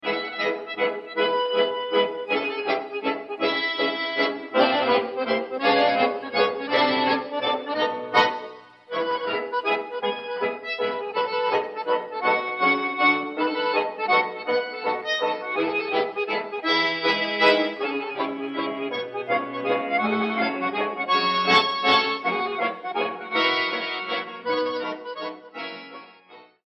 solo accordionist